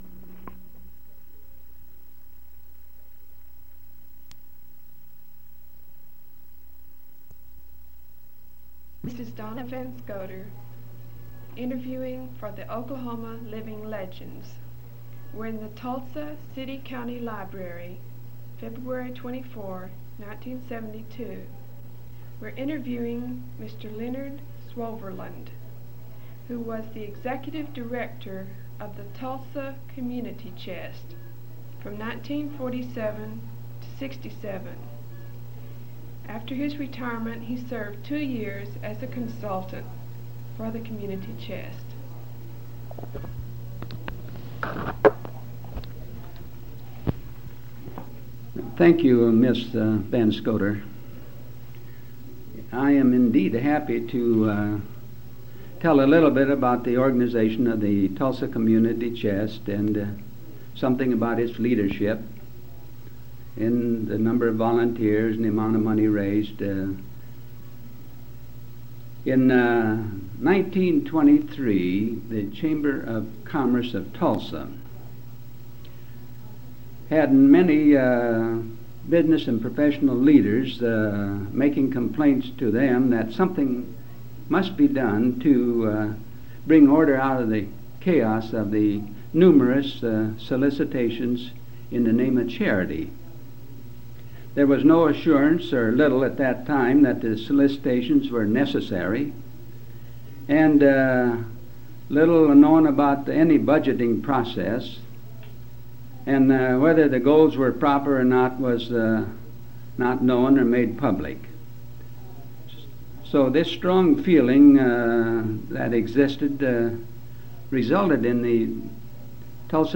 oral history interviews